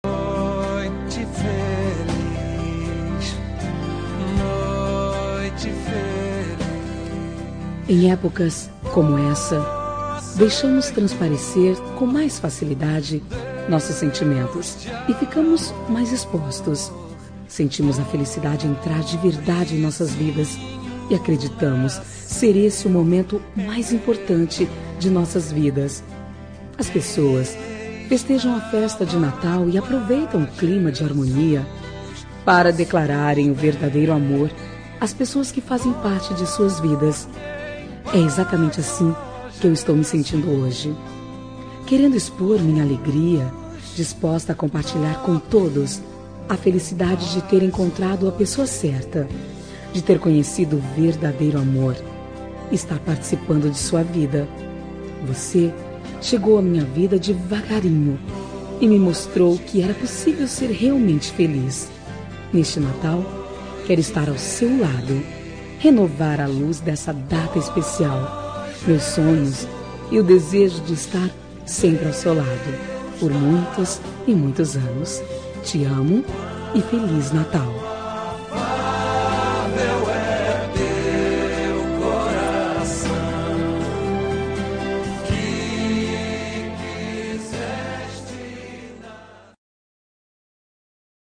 Natal Pessoa Especial – Voz Feminina – Cód: 348244